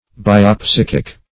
Search Result for " biopsychic" : The Collaborative International Dictionary of English v.0.48: Biopsychic \Bi`o*psy"chic\, Biopsychical \Bi`o*psy"chic*al\, a.]